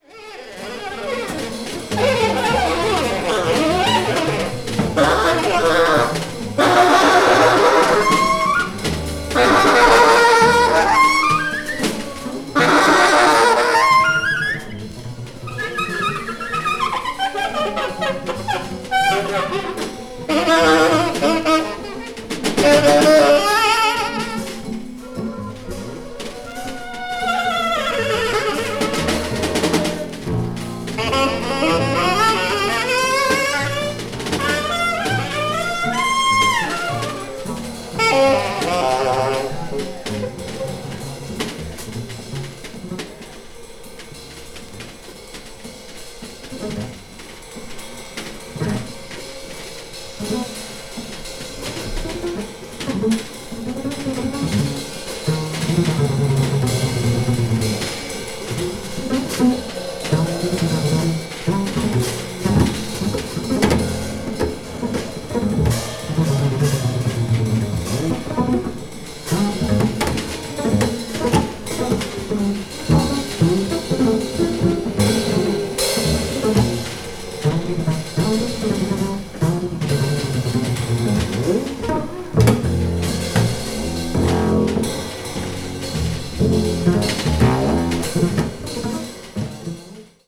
media : EX-/EX-(light noise caused by slightly hairlines.)
avant-jazz   free improvisation   free jazz   spiritual jazz